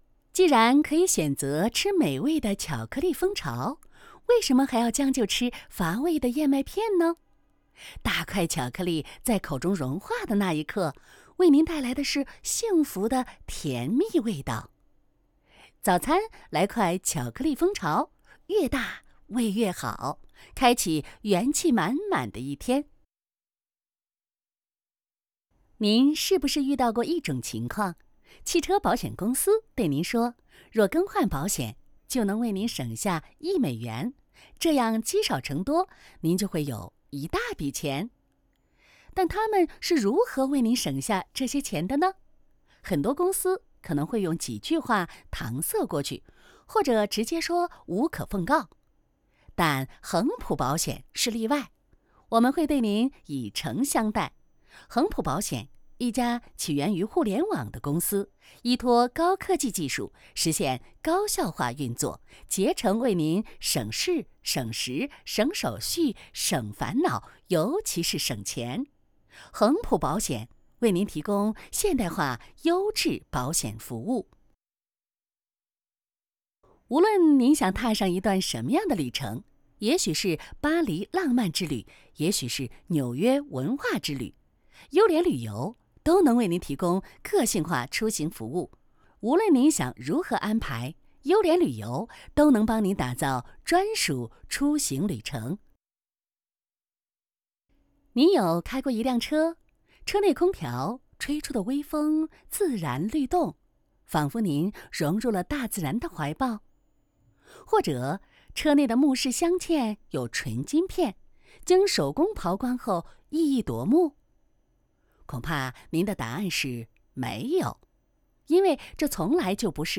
Chinese_Female_001VoiceArtist_40Hours_High_Quality_Voice_Dataset
Advertising Style Sample.wav